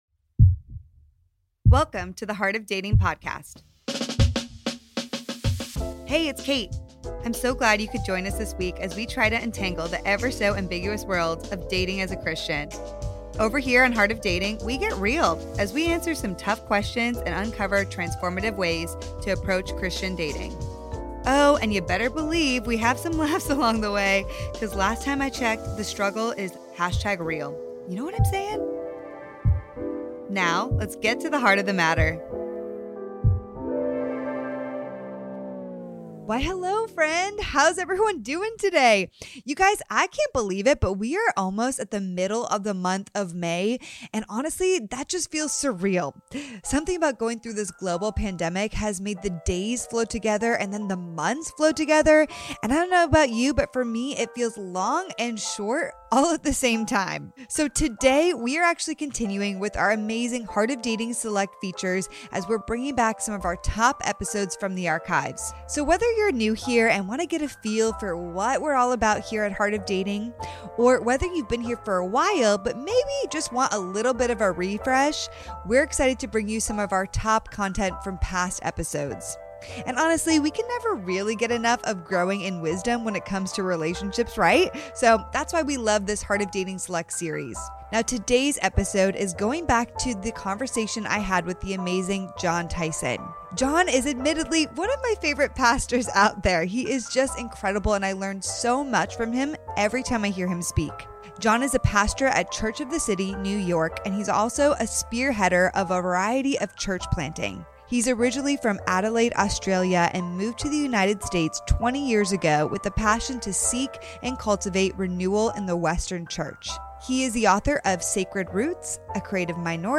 In this conversation, we answer questions that YOU sent in about regarding singleness, a theology of dating, and sexual formation!